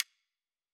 Sound / Effects / UI / Minimalist6.wav